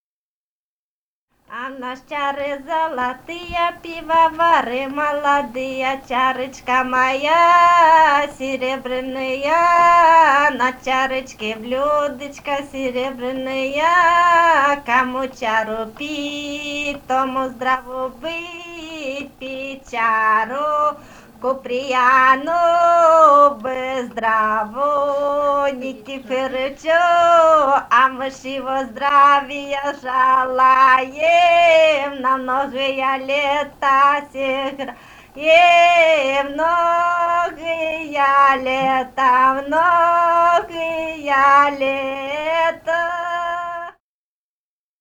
полевые материалы
«А у нас чары золотые» (свадебная).
Румыния, с. Переправа, 1967 г. И0974-15